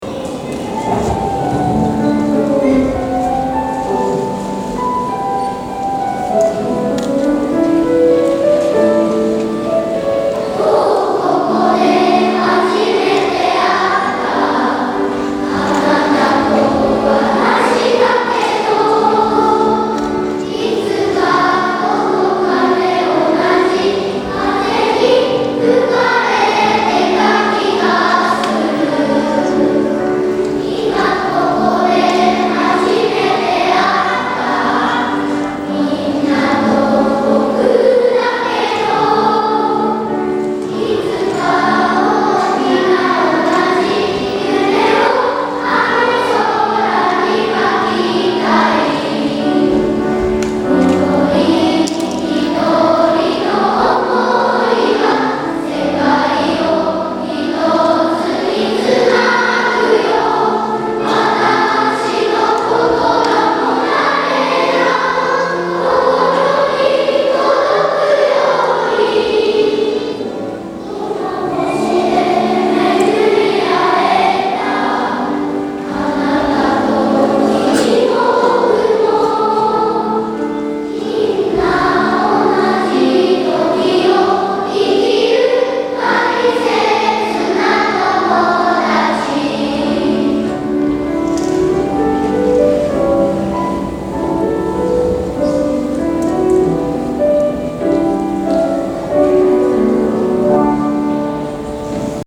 大空創立記念コンサート
この地球（ほし）の友だちだから」全校２部合唱です。